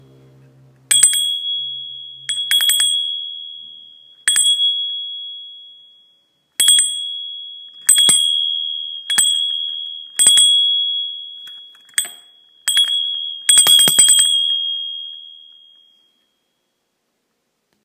Litinový zvonek na konzolce
Uvidíte, jak zvuk litinového zvonu krásně oživí tuto běžnou činnost.
Materiál: litinová konzolka, zvoneček i srdce zvonku.
litinovy-zvonek-na-konzolce.m4a